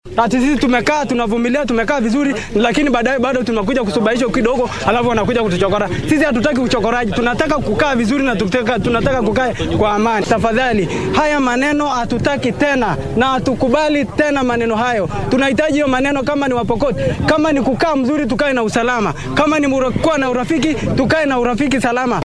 Mid ka mid ah shacabka ku nool magaalada Lodwar ee ismaamulka Turkana ayaa walaac ka muujiyay dhacdooyinka amni darro ee soo noqnoqday.